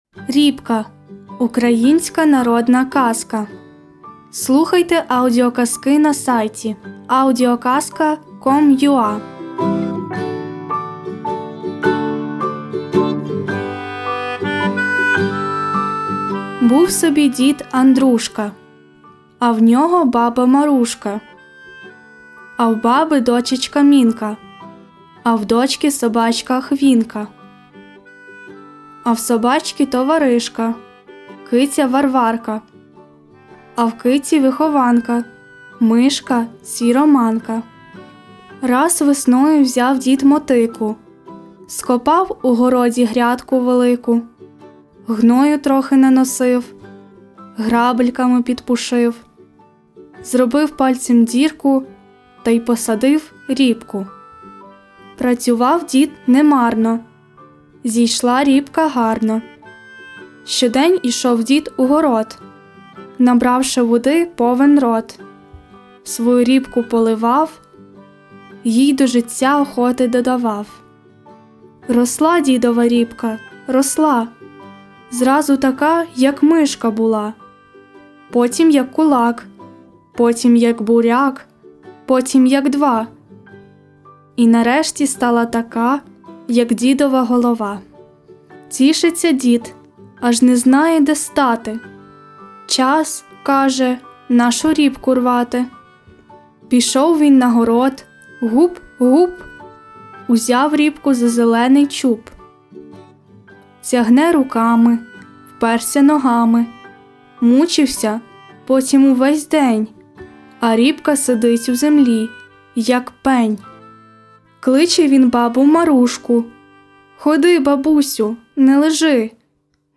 Аудіоказка Ріпка
Читає гидко
Дуже погана озвучка! Без емоцій...